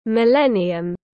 Thiên niên kỷ tiếng anh gọi là millennium, phiên âm tiếng anh đọc là /mɪˈlen.i.əm/